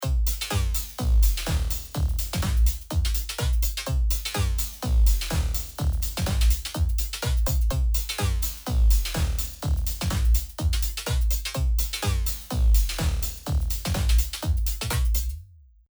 次にブロック2で同じようにCombをかけてみます。
なるほど！このXYパネルの円の形でかかり具合が異なりますね。